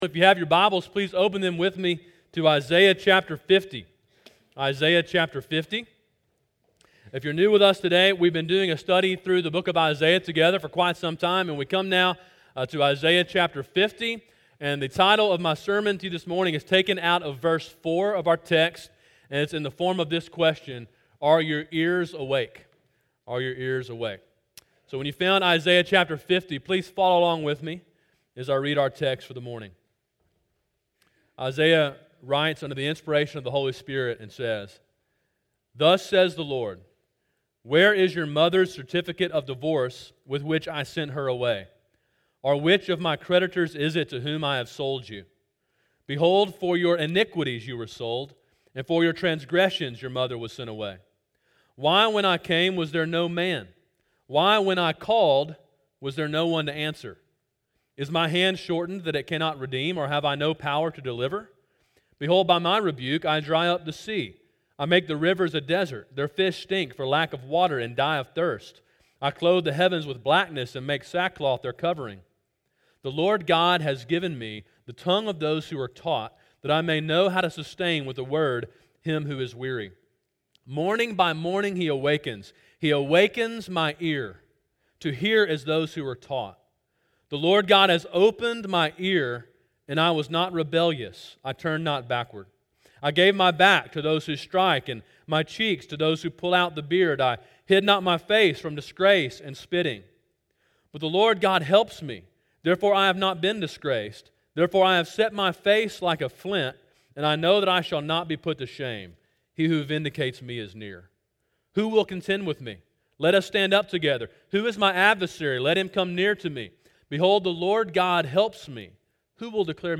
Sermon: “Are Your Ears Awake?”
Sermon in a series on the book of Isaiah.